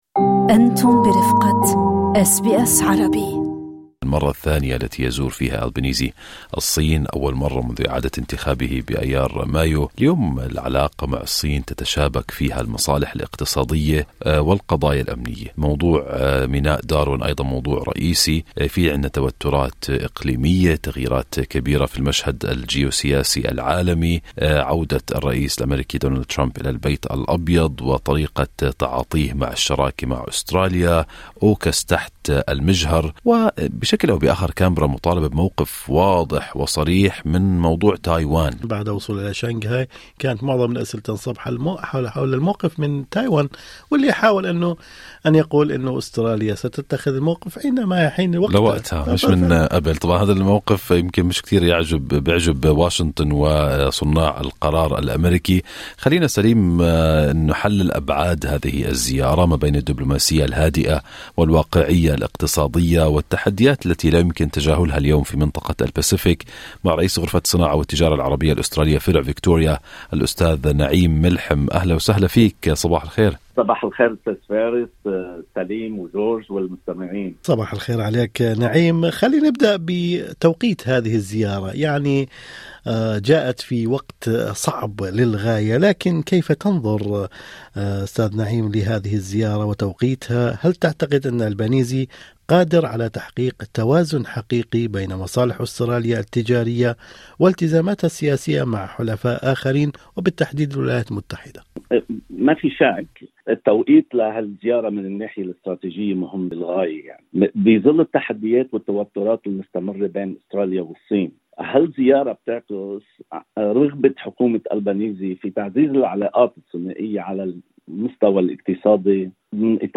في ظل تعقيدات المشهد الجيوسياسي وتحديات التوازن بين التحالفات السياسية والمصالح الاقتصادية، يزور رئيس الوزراء الأسترالي أنتوني ألبانيزي الصين في زيارة دبلوماسية تستمر أسبوعًا. وفي هذا السياق، أجرينا مقابلة